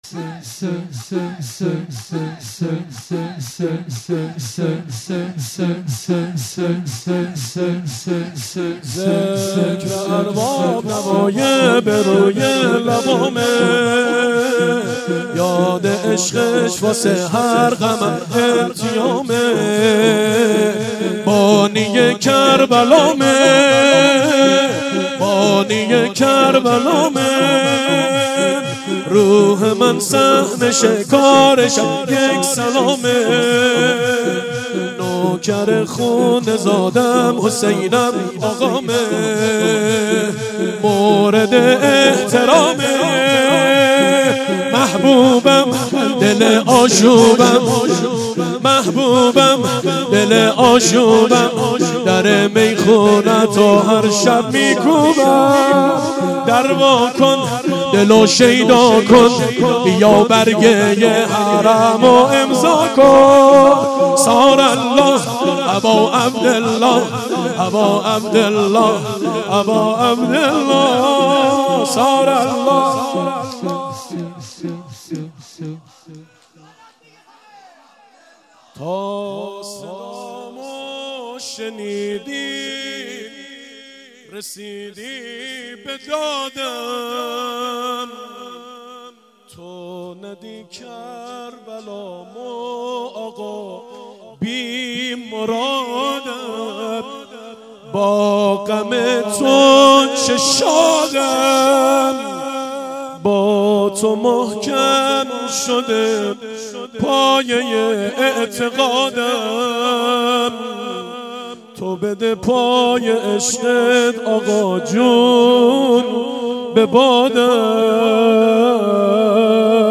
شور3